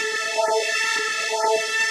SaS_MovingPad03_125-A.wav